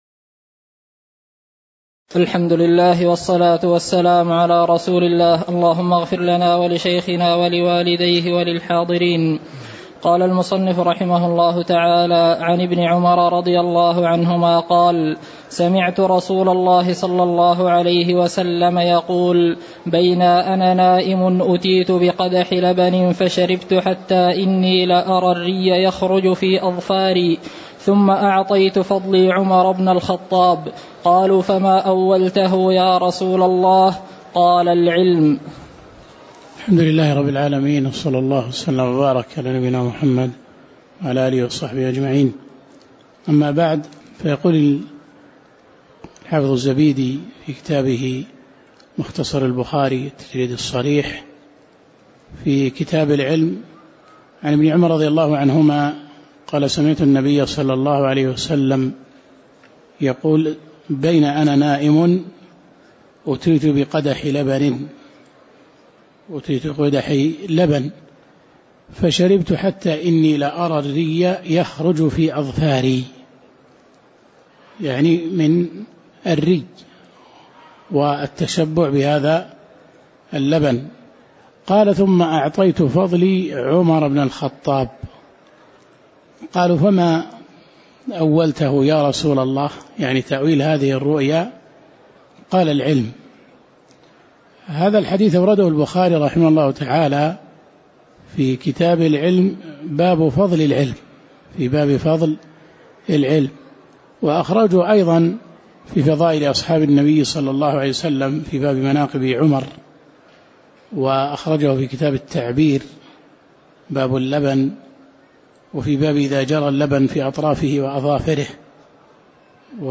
تاريخ النشر ١٣ صفر ١٤٤٠ هـ المكان: المسجد النبوي الشيخ